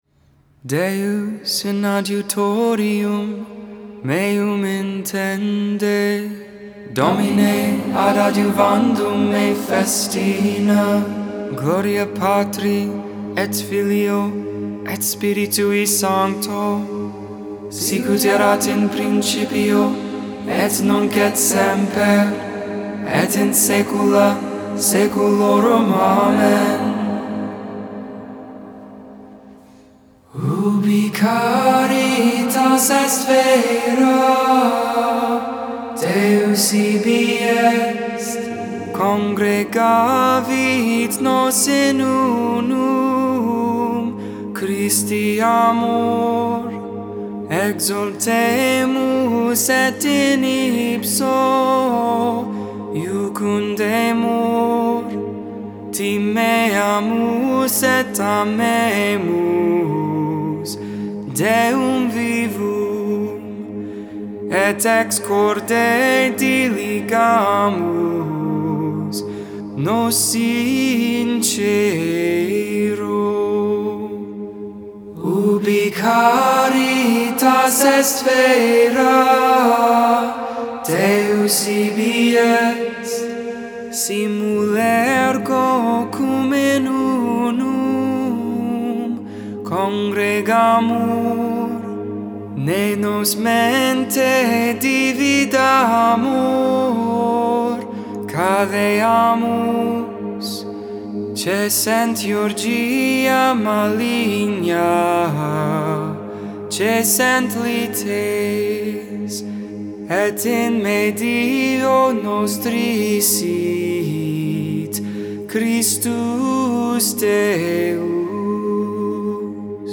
4.1.21-Vespers-Thurs-Holy.mp3